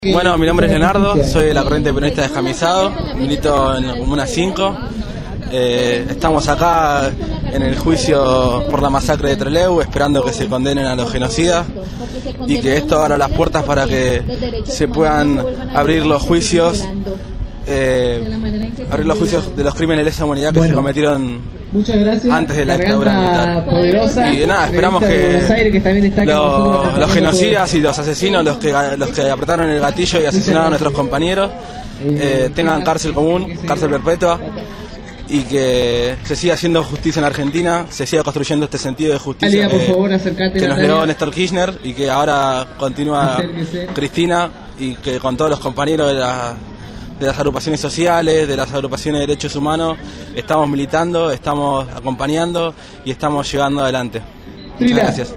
La sentencia se conoció en el centro Cultural Municipal «José Hernández», de Rawson.
Los enviados especiales de Radio Gráfica recopilaron los testimonios de los mismos.